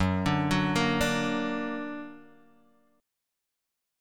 Gb+ chord